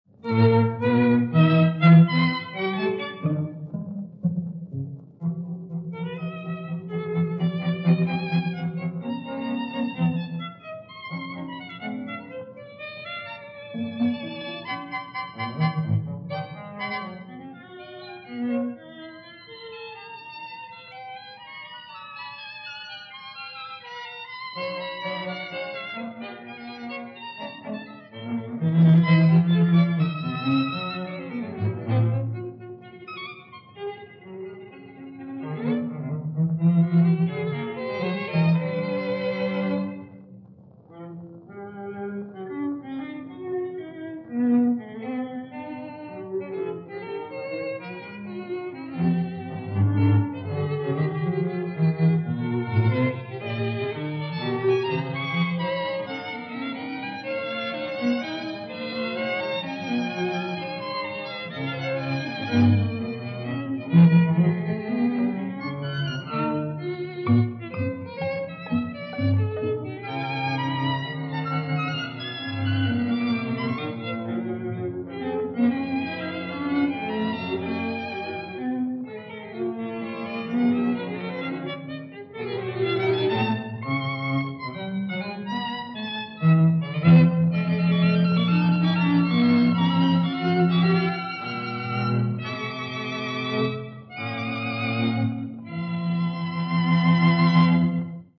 Streichquartettt) (1967)